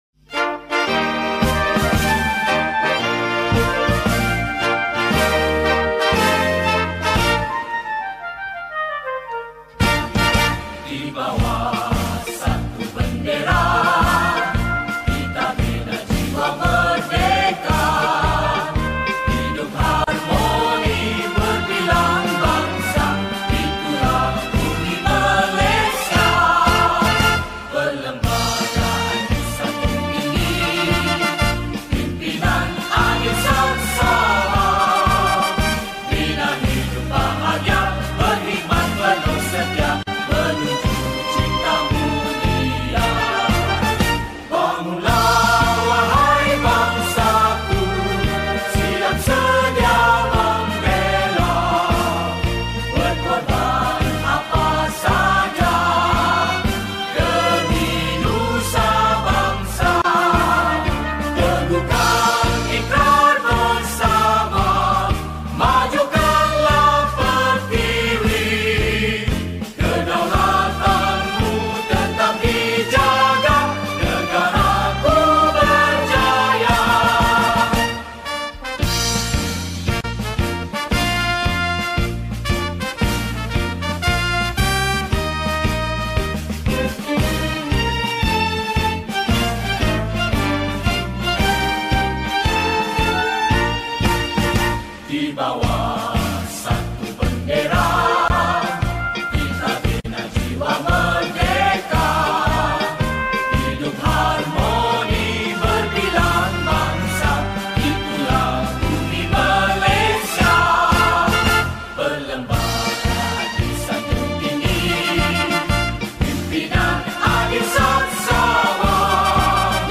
Recorder arrangement By